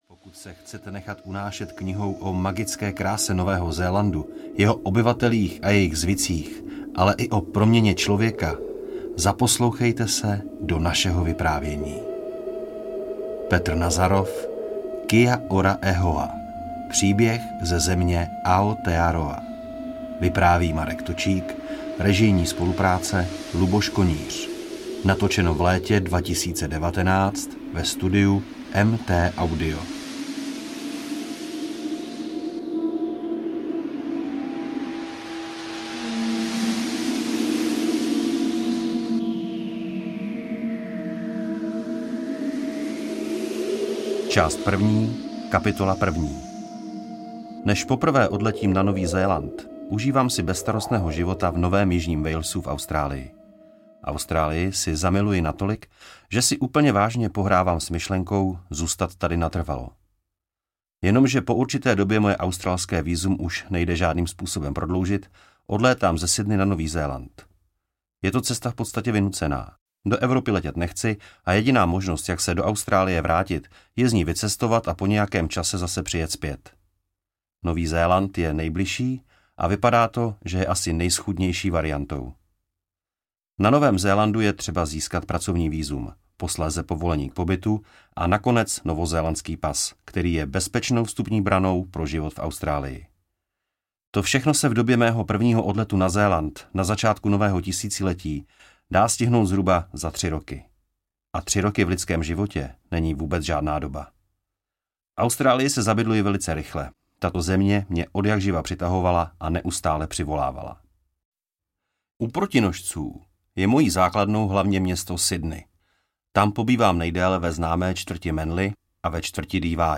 Kia Ora E Hoa audiokniha
Ukázka z knihy